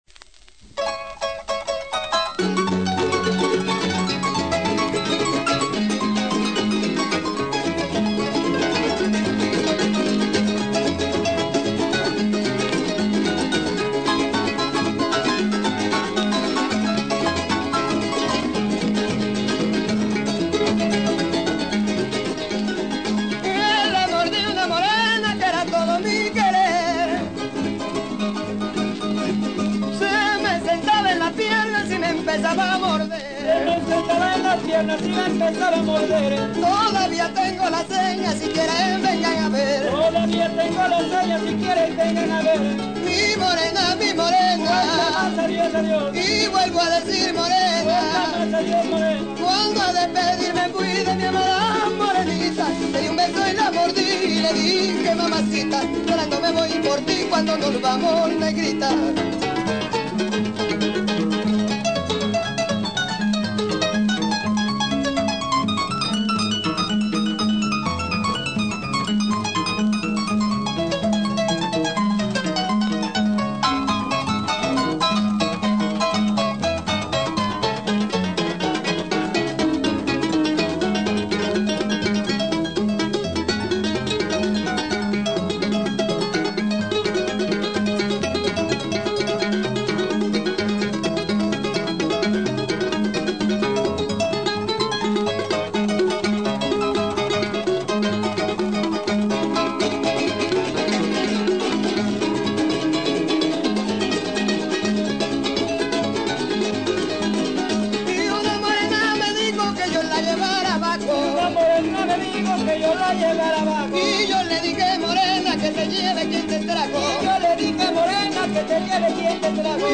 Son jarocho.
Grabado en Los Naranjos
arpa grande
requinto cuatro
jarana tercera.
Uno de los sones más sentimentales, derivación clásica del fandango español. Esta versión presenta un ejemplo del contrapunto melódico centelleante entre arpa y requinto.